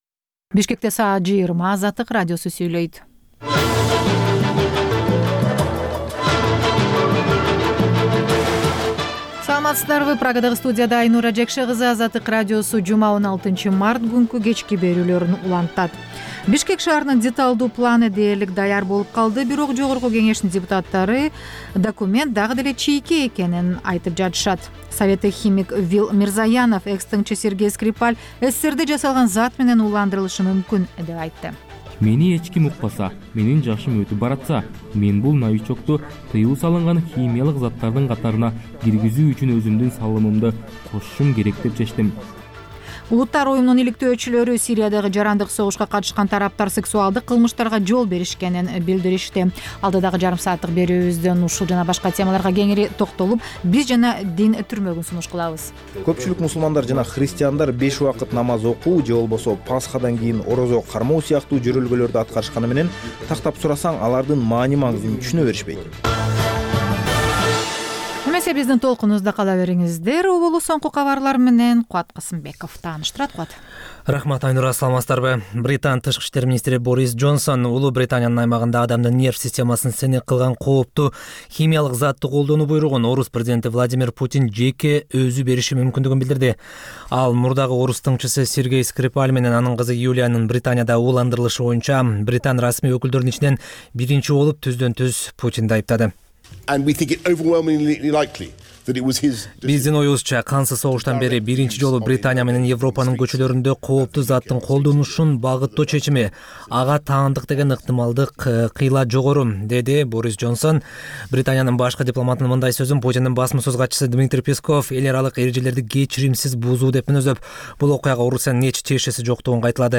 Бул үналгы берүү ар күнү Бишкек убакыты боюнча саат 20:00дан 21:00гө чейин обого түз чыгат.